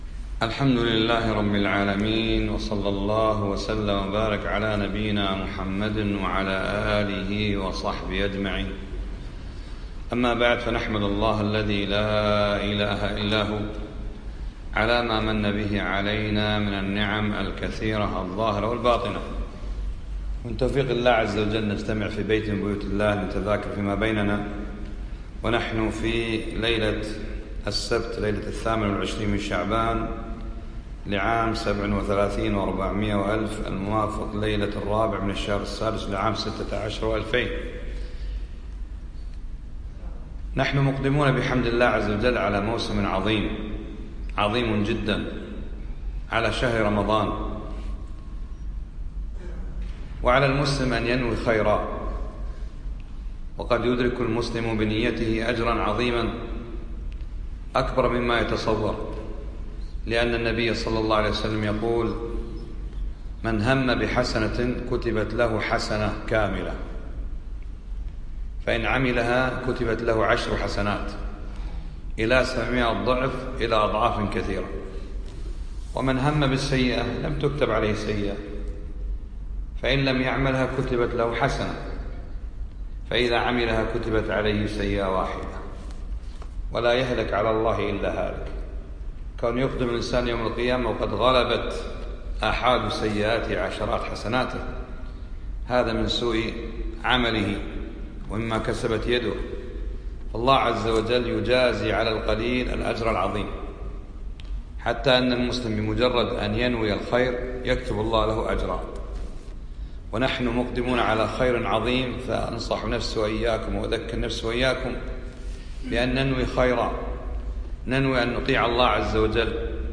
يوم الجمعة 27 شعبان 1437هـ الموافق 3 6 2016م في مسجد عايض المطيري الفردوس